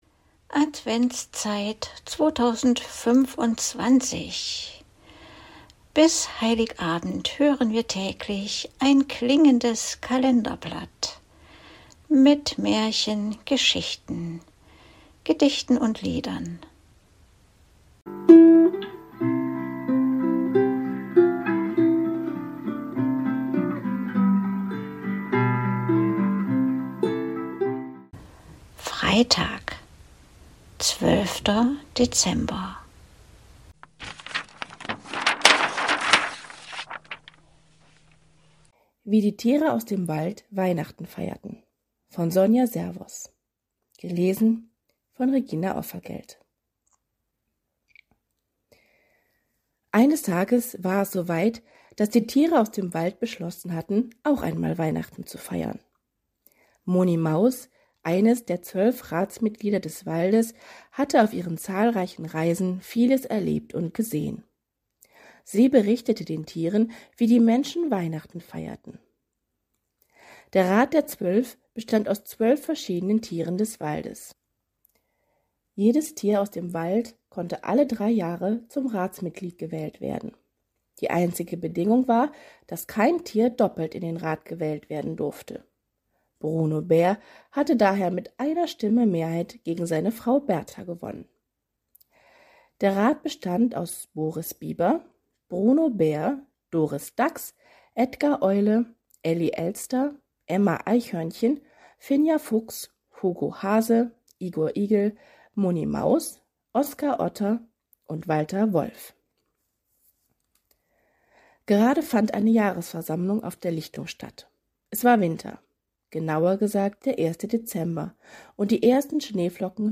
präsentiert seine Musik “Winterwald".